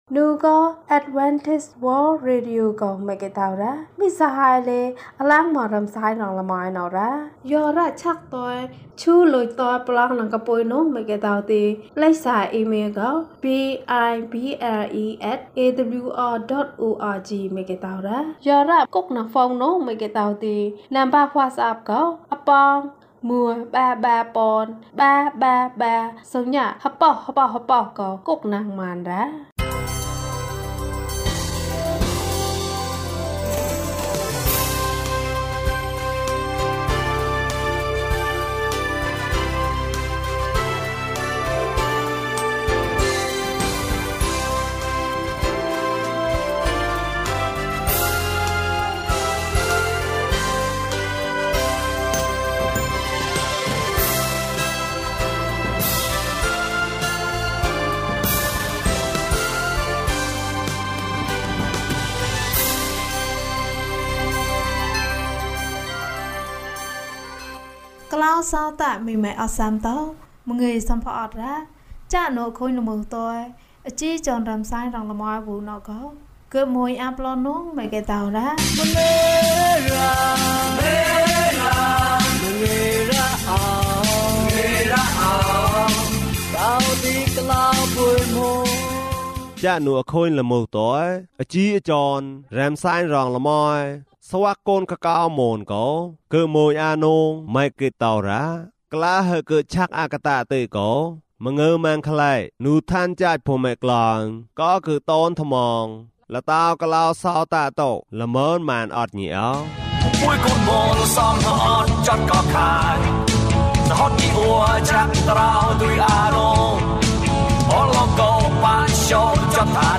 ခရစ်တော်ထံသို့ ခြေလှမ်း။၀၂ ကျန်းမာခြင်းအကြောင်းအရာ။ ဓမ္မသီချင်း။ တရားဒေသနာ။